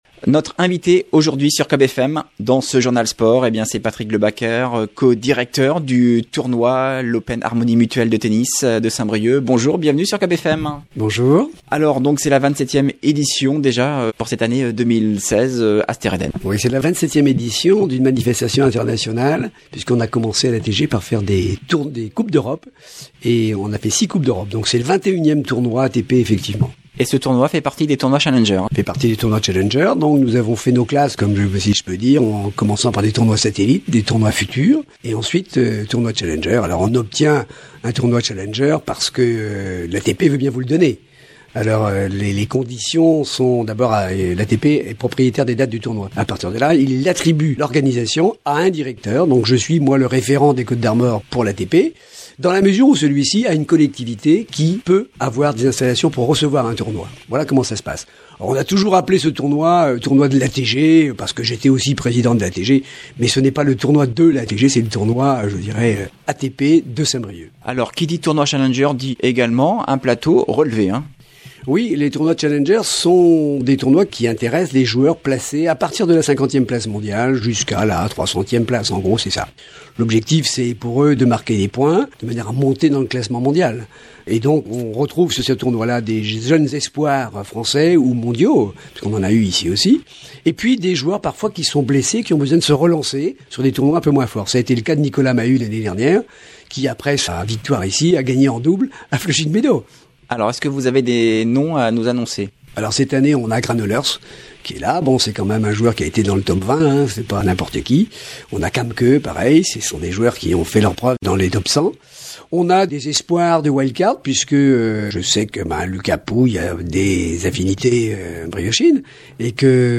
était notre invité hier soir dans le Journal Sports